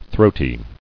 [throat·y]